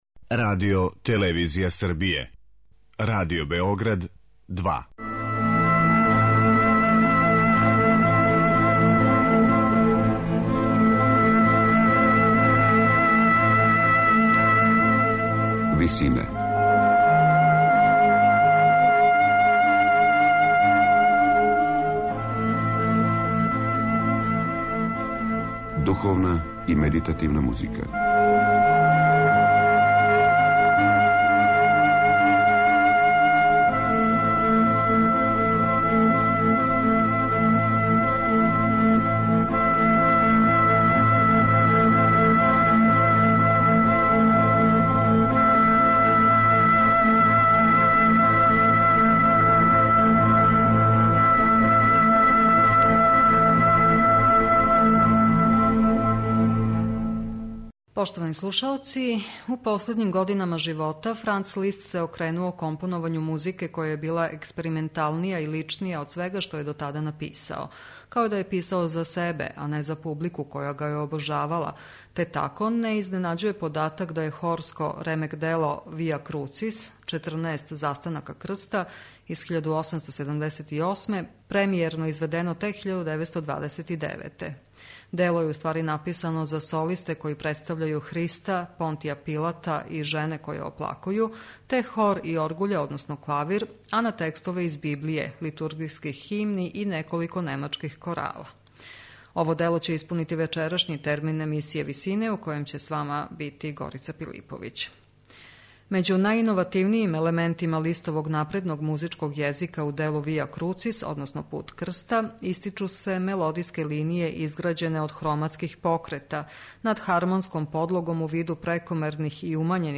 Дело је написано за солисте који представљају Христа, Понтија Пилата и жене које оплакују, те хор и оргуље, односно клавир, а на текстове из Библије, литургијских химни и неколико немачких корала.